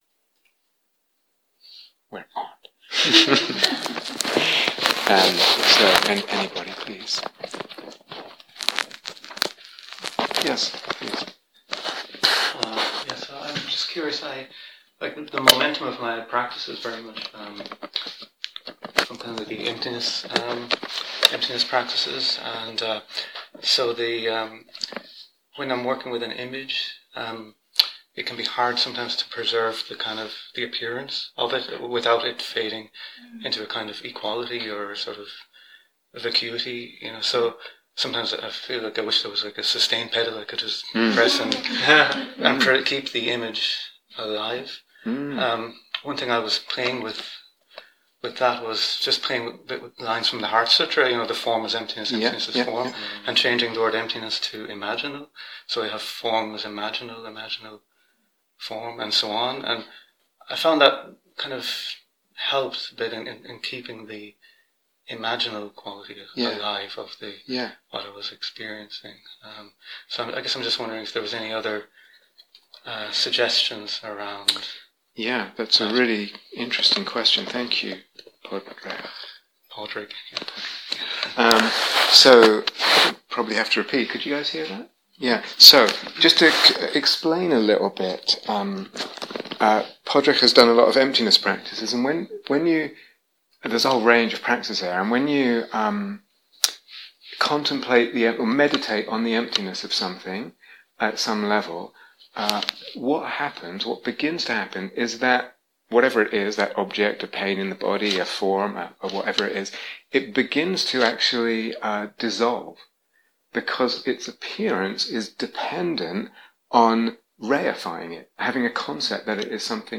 Please Note: This series of teachings is from a retreat for experienced practitioners